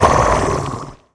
fall_1.wav